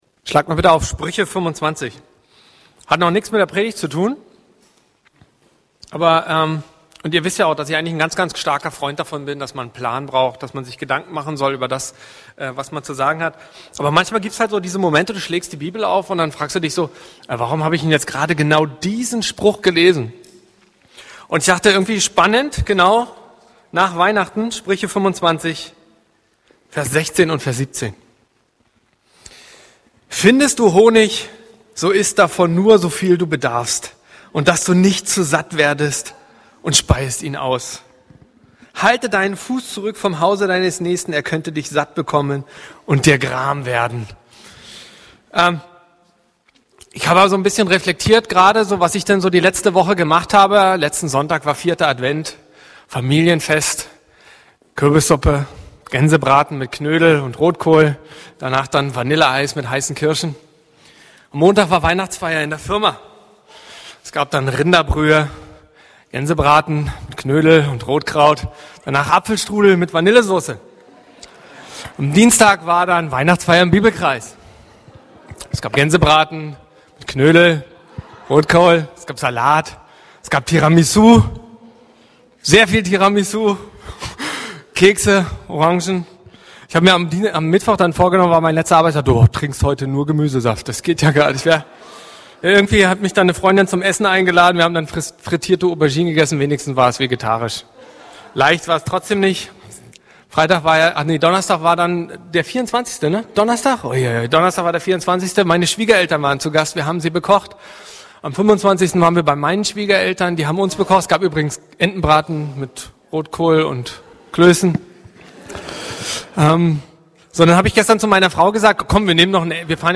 Predigten - Berliner Gemeinde Christi